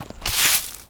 sweeping_broom_leaves_stones_05.wav